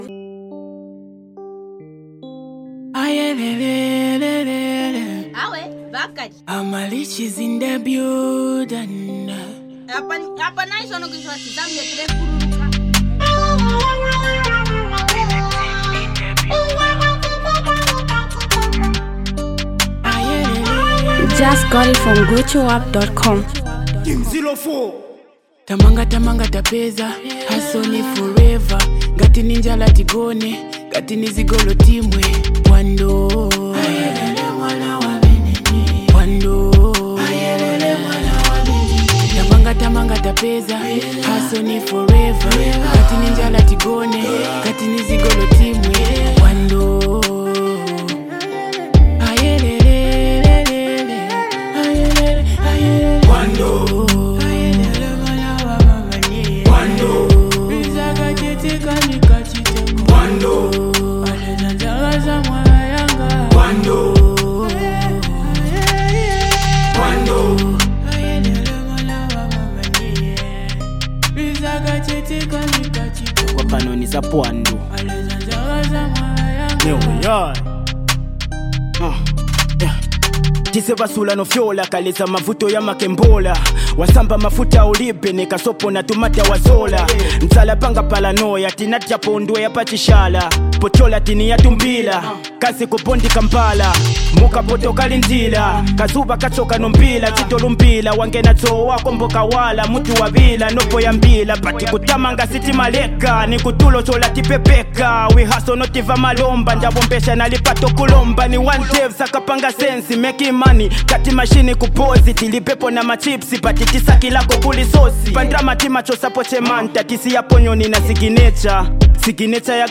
rap
is another Ghetto Hip-Hop Track beat You Need To Listen To.